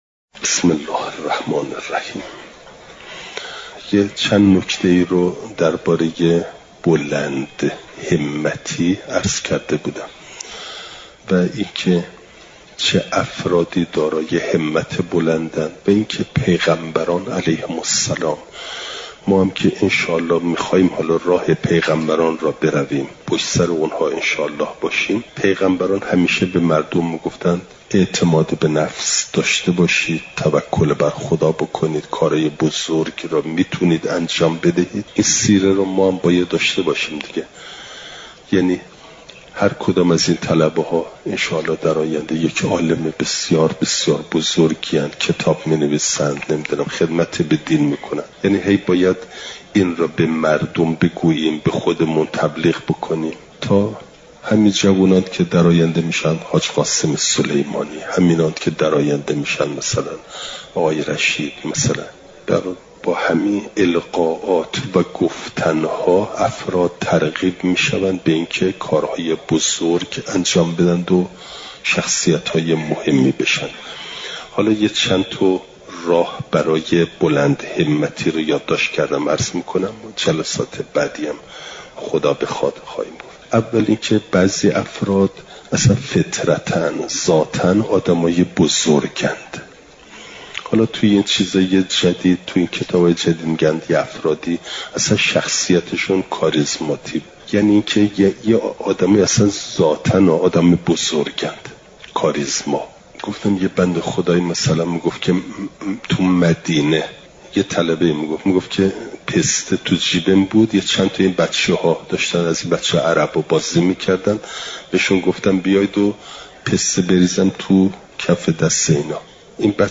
بیانات اخلاقی
چهارشنبه ۲۸ آبانماه ۱۴۰۴، حرم مطهر حضرت معصومه سلام ﷲ علیها